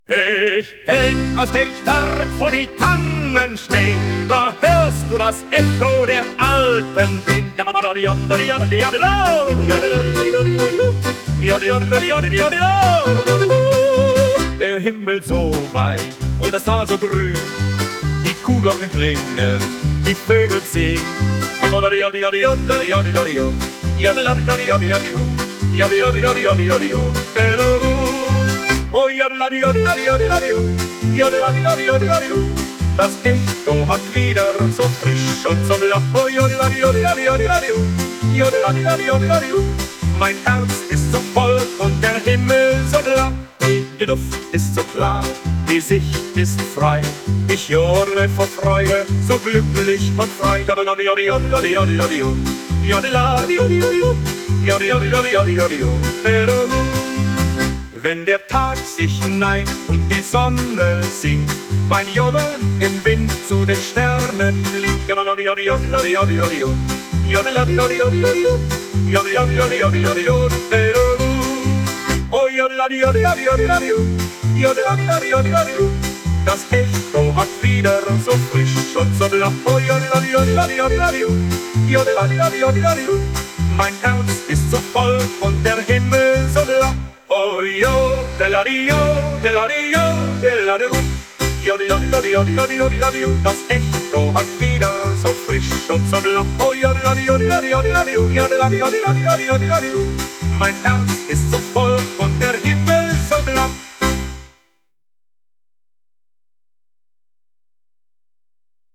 Der Vorgang ist der gleiche: man gibt einen Promt ein, in dem man beschreibt, was man haben möchte und ZACK wird einem ein fertiges Musikstück angeboten.
Als erstes habe ich mir angehört, was andere schon so erstellt haben und bin zufällig auf ein Stück gestoßen, das folgende Kennzeichen aufweist: „[deutscher-schlager], [traditional-bavarian], [accordion], [virtuoso-yodeling]“ Den Promt des Kollegen bekommt man leider nicht zu sehen, aber man kann die KI auffordern, ein ähnliches Stück, äh – zu komponieren, zu klauen, zusammenzupuzzeln, zu generieren?
Es ist ein Computerprogramm, das die Musik erstellt!